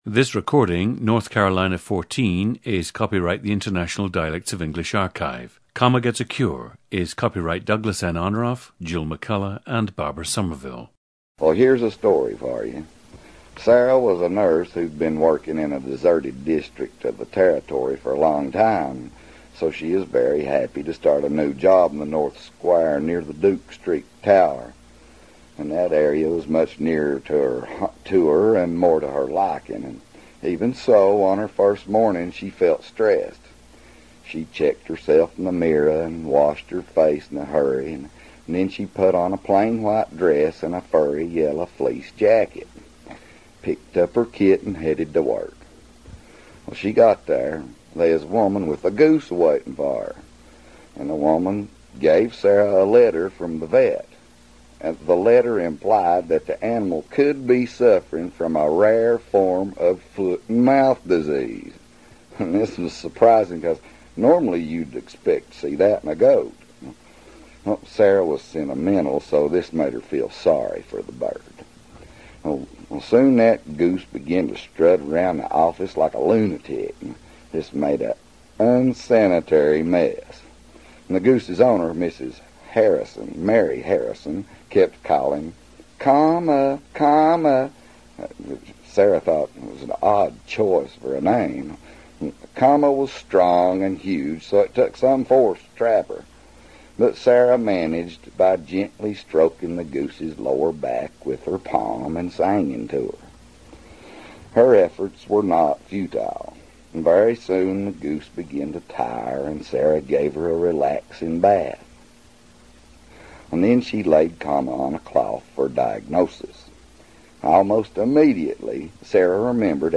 GENDER: male
Subject’s parents are from Yancey County too, and despite having lived for five years in Huntsville, Texas, he assures me his is a proper Yancey County accent.
The subject uses a retracted “r,” tends to drop consonants (“sentimental becomes “senimenal,” and “for her” becomes “for’er”), and consistently contracts “-ing” to “-in (“likin’,” “mornin’,” “callin'”).
The diphthong in “white” and “time” loses its second element.
Special pronunciations include “think,” which almost becomes “thank”; “singin’,” which becomes “sangin'” (the “g” is not hard); and “can’t,” which becomes “cain’t.”
• Recordings of accent/dialect speakers from the region you select.